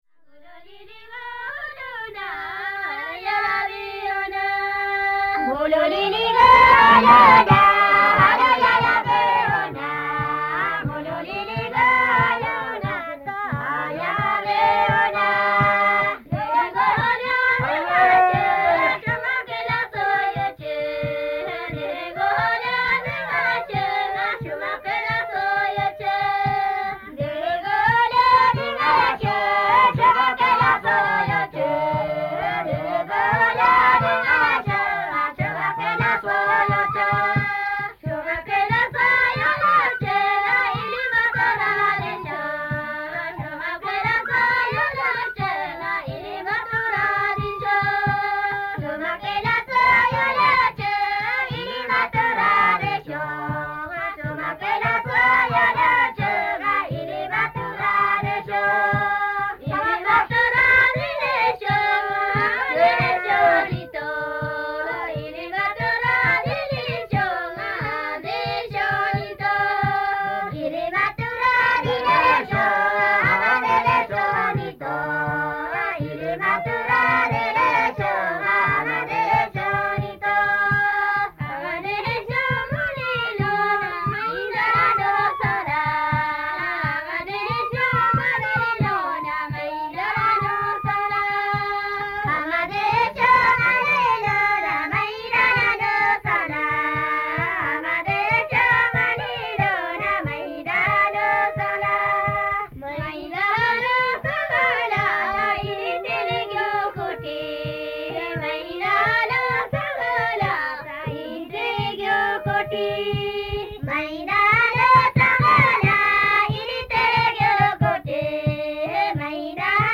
Women singing with flute
From the sound collections of the Pitt Rivers Museum, University of Oxford, being from a collection of reel-to-reel recordings of music and spoken language (principally Thulung Rai)